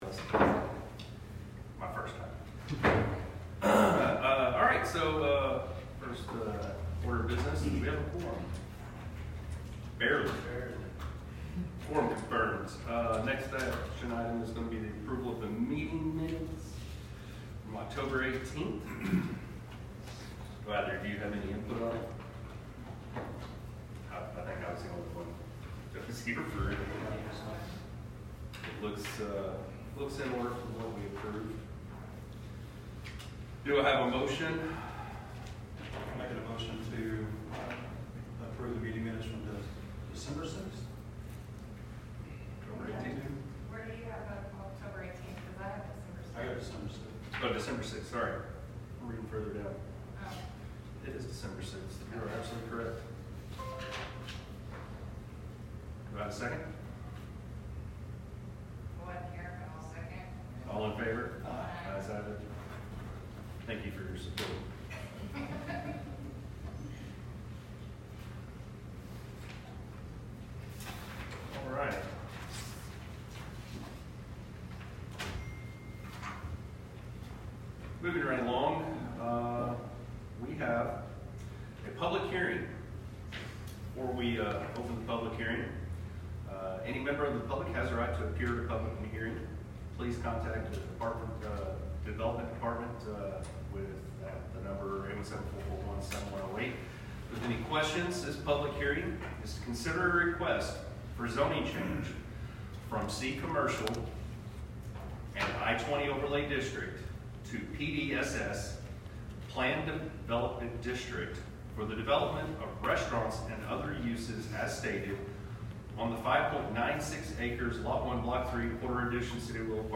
Location and Time: El Chico City Hall, 120 El Chico, Suite A, Willow Park, Texas 76087, 6PM